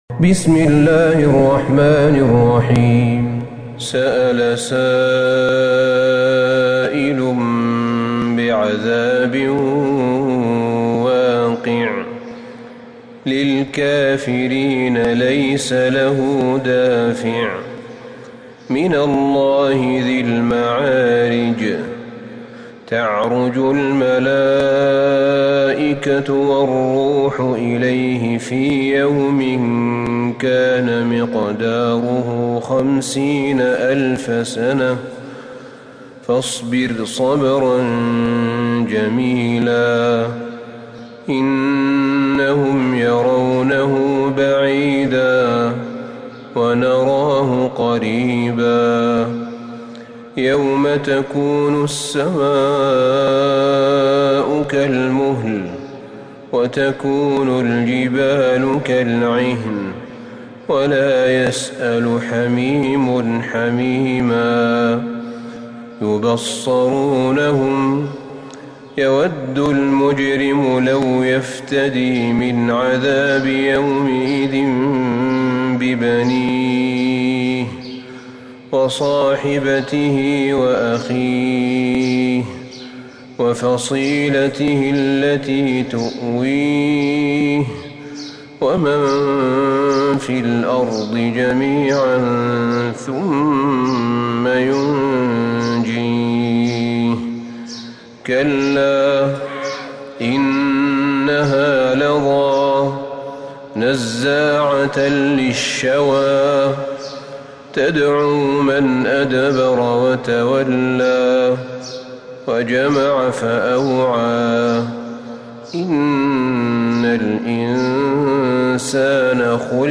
سورة المعارج Surat Al-Maarij > مصحف الشيخ أحمد بن طالب بن حميد من الحرم النبوي > المصحف - تلاوات الحرمين